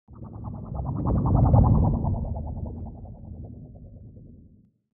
دانلود آهنگ کشتی 4 از افکت صوتی حمل و نقل
جلوه های صوتی
دانلود صدای کشتی 4 از ساعد نیوز با لینک مستقیم و کیفیت بالا